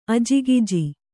♪ ajigiji